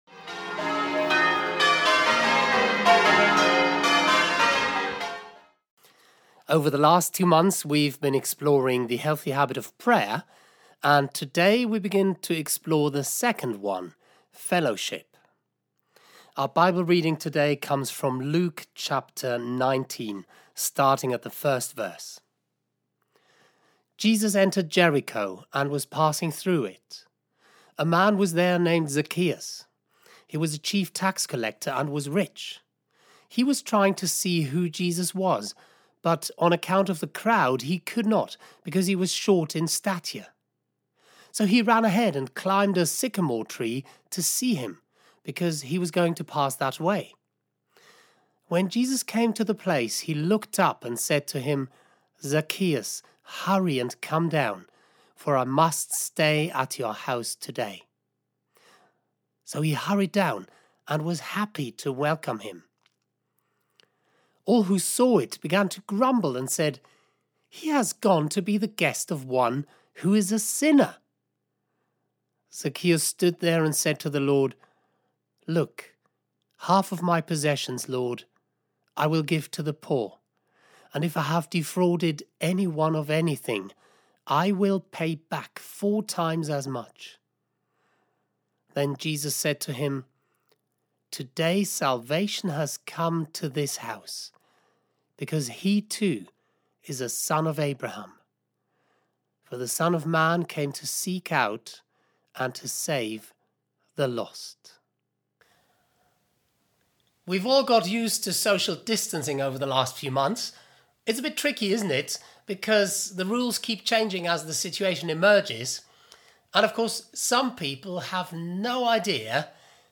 Audio Service for Sunday 2 August: The Healthy Habit of Fellowship
(The Bible Reading is included in the service)